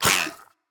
Minecraft Version Minecraft Version snapshot Latest Release | Latest Snapshot snapshot / assets / minecraft / sounds / mob / dolphin / attack1.ogg Compare With Compare With Latest Release | Latest Snapshot